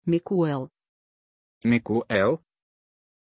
Aussprache von Miquel
pronunciation-miquel-ru.mp3